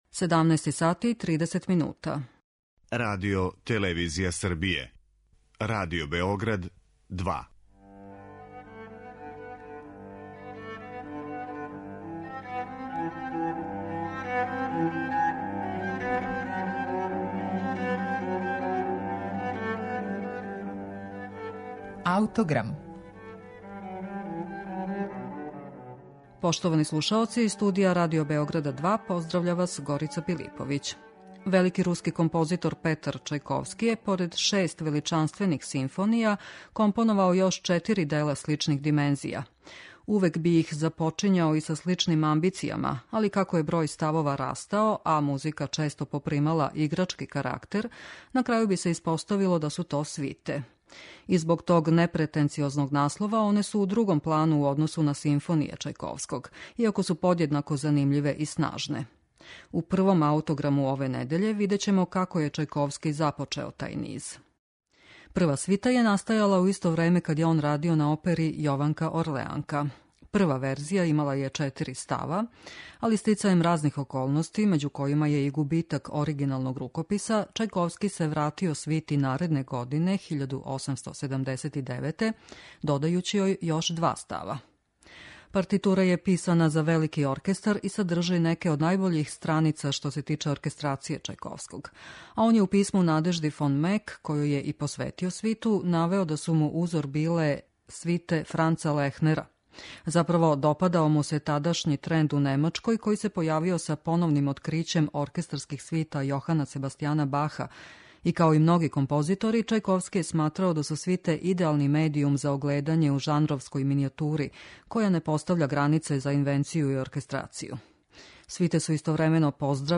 Партитура је писана за велики оркестар и садржи неке од најбољих страница што се тиче оркестрације Чајковског.